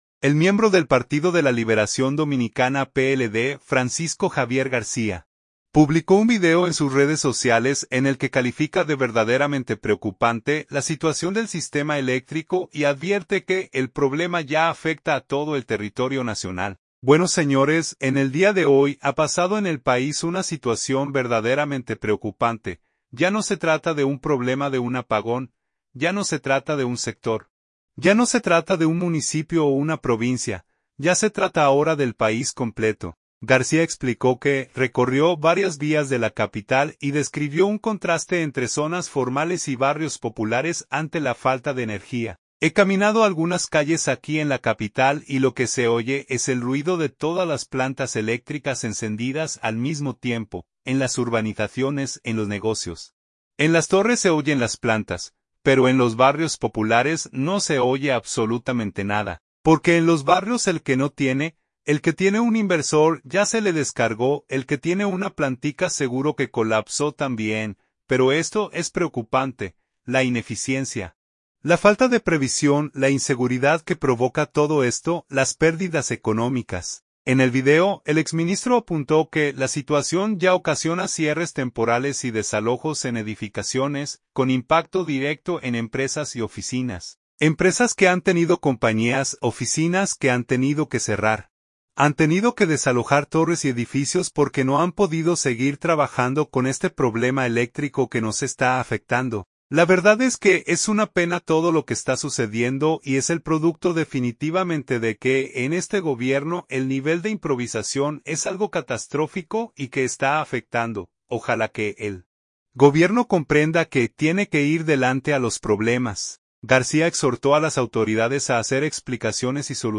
El miembro del Partido de la Liberación Dominicana (PLD), Francisco Javier García, publicó un video en sus redes sociales en el que califica de “verdaderamente preocupante” la situación del sistema eléctrico y advierte que el problema ya afecta a todo el territorio nacional.